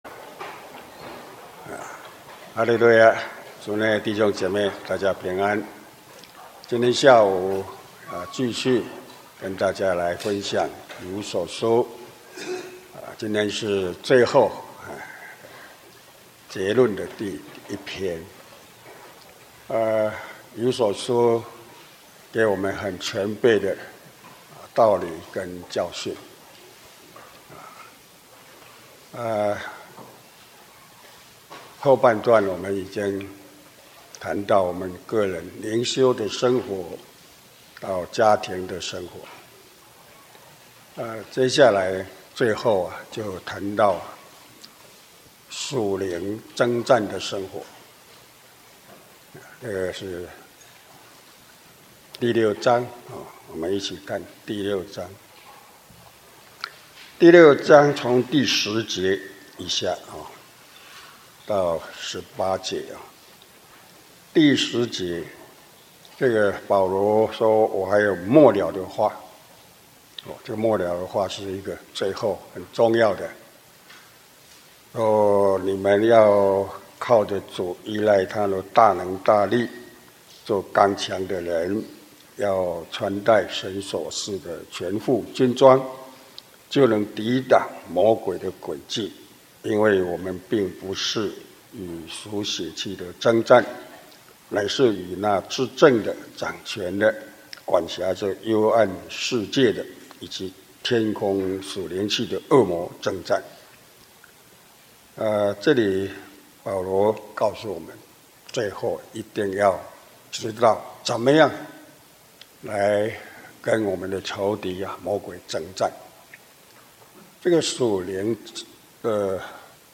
聖經講座-以弗所書(九)-講道錄音